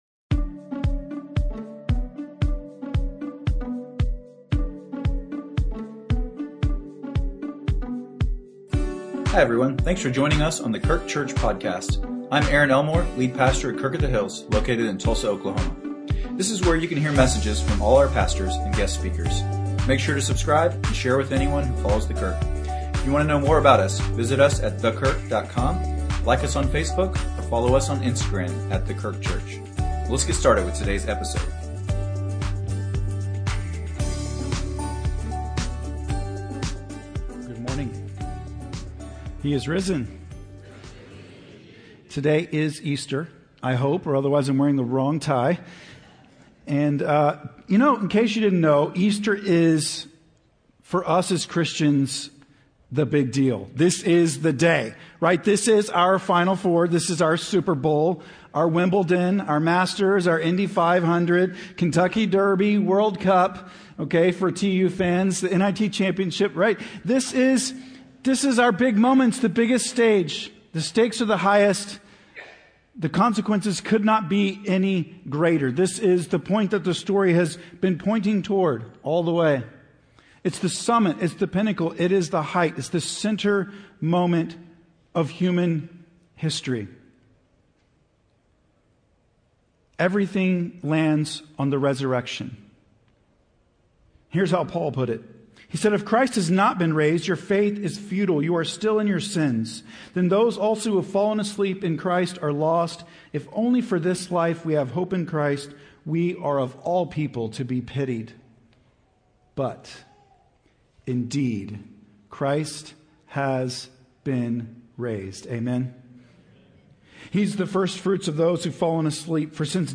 Sermons | The Kirk - Tulsa, OK